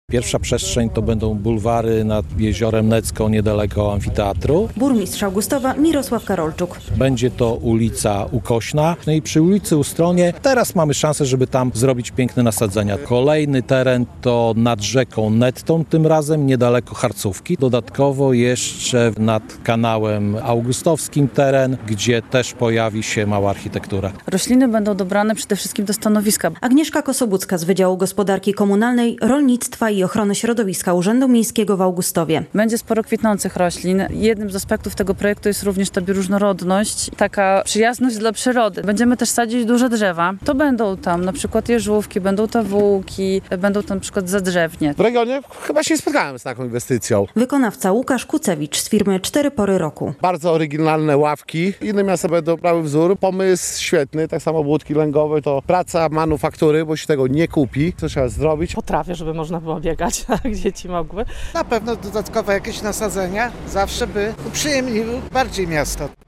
Zielone strefy w Augustowie - relacja
Burmistrz Mirosław Karolczuk zapowiedział miejsca, które staną się zielonymi oazami.